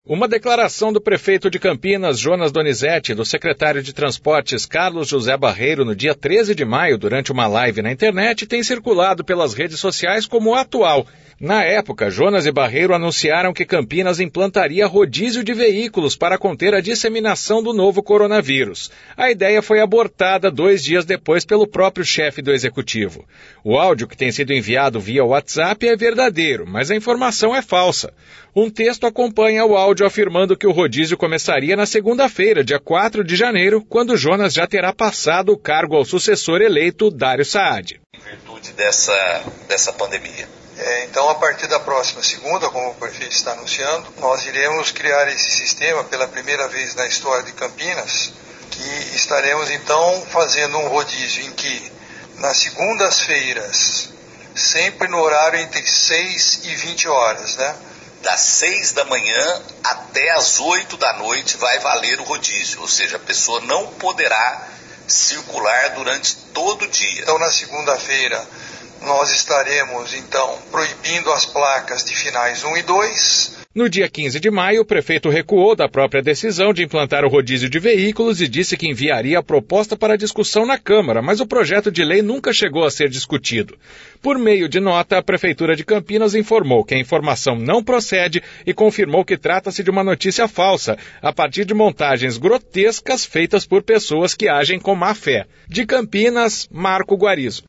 Uma declaração do prefeito de Campinas, Jonas Donizette, e do secretário de Transportes, Carlos José Barreiro, no dia 13 de maio durante uma live na internet tem circulado pelas redes sociais como atual.